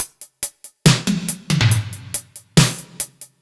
Index of /musicradar/french-house-chillout-samples/140bpm/Beats
FHC_BeatC_140-01_NoKick.wav